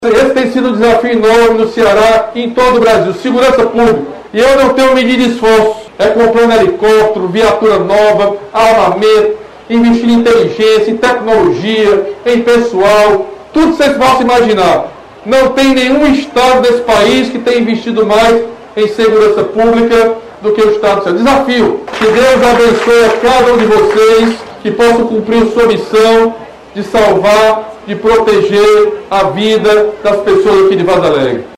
O governador Camilo Santana destacou o trabalho que o Estado vem promovendo para levar mais segurança à população.